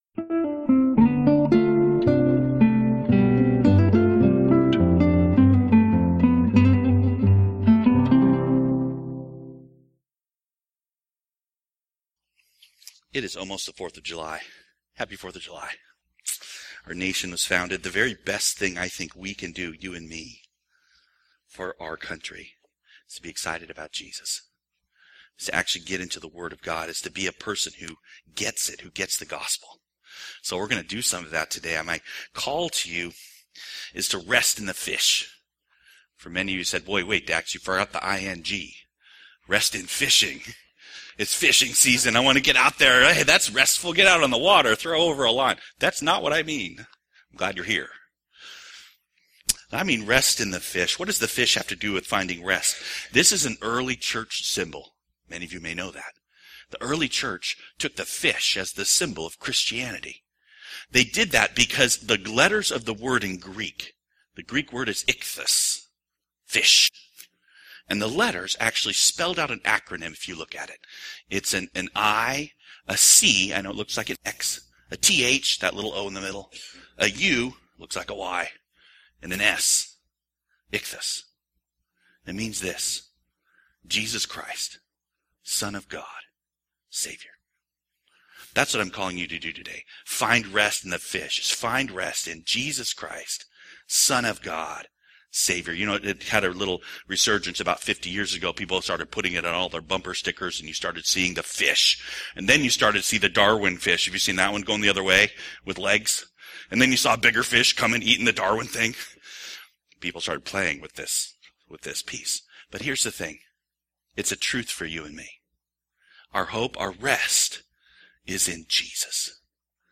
Sermon Archives-Matthew